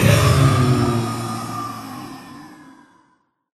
mob / blaze / death.ogg
death.ogg